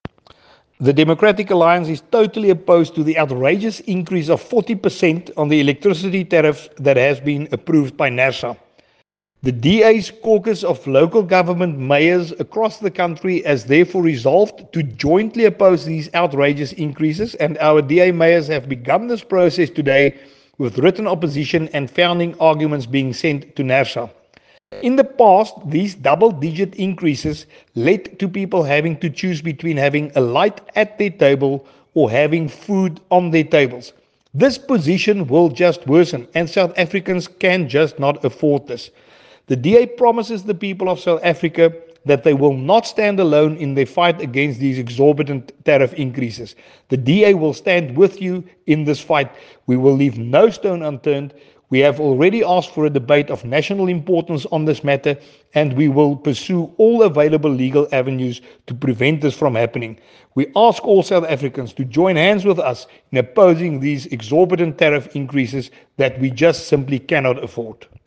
soundbite by Willie Aucamp MP.